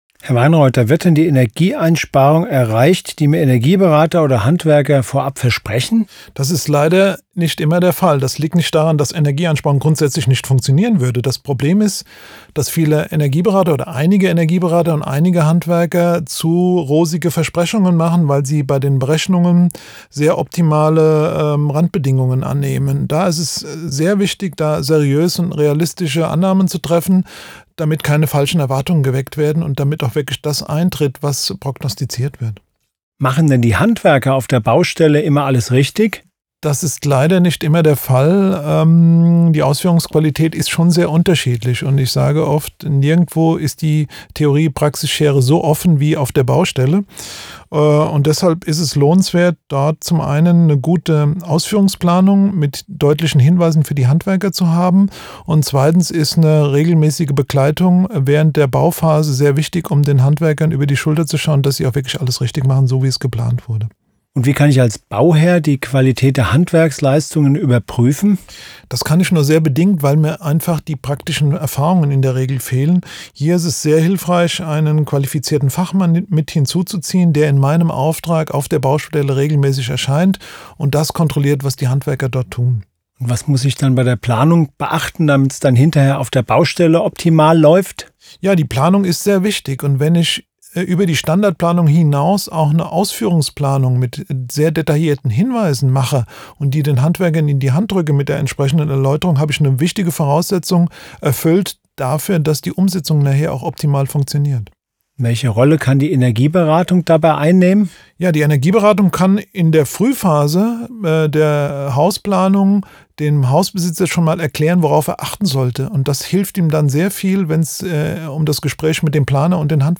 Interview zu wichtigen Energiethemen von A wie Atmende Wände über K wie Kellerdämmung bis W wie Wärmepumpe.